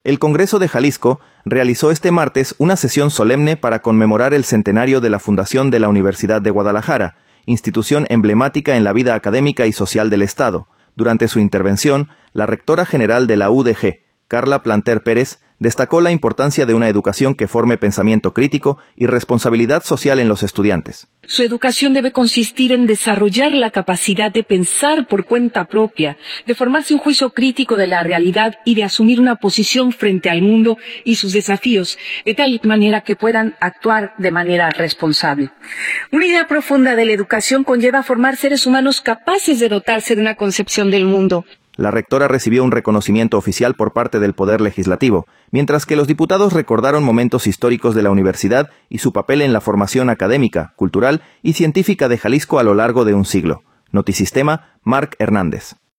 El Congreso de Jalisco realizó este martes una sesión solemne para conmemorar el centenario de la fundación de la Universidad de Guadalajara (UdeG), institución emblemática en la vida académica y social del estado.